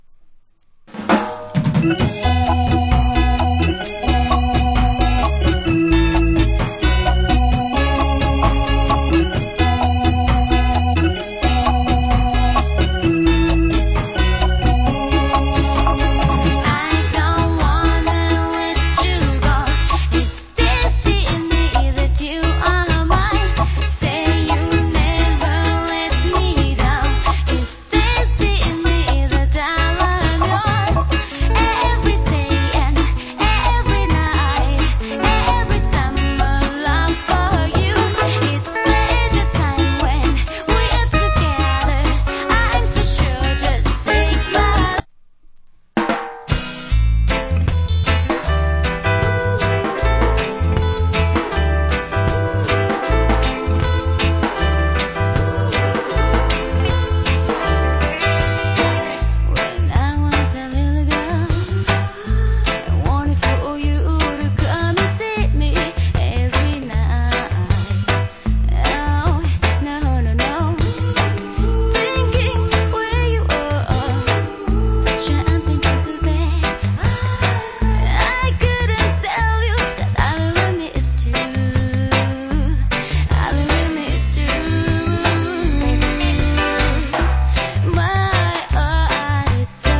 Cool Rock Steady Vocal.